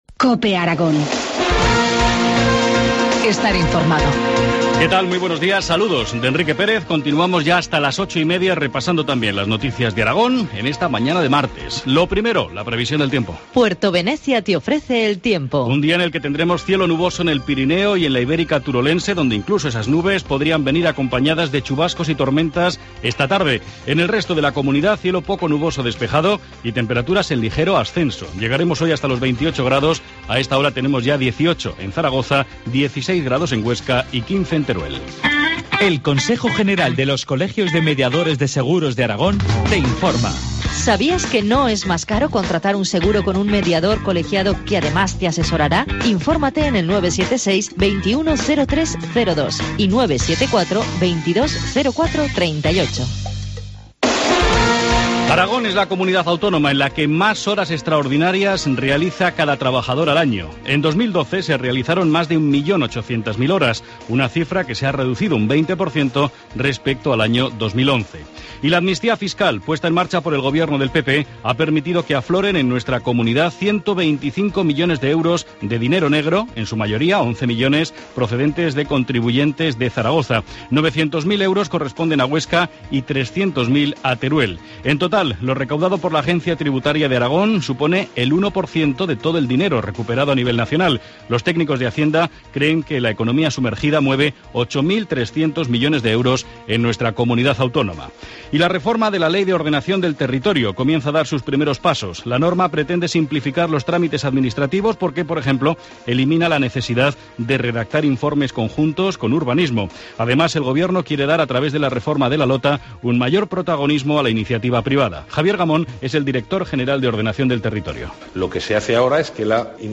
Informativo matinal, martes 10 de septiembre, 8.25 horas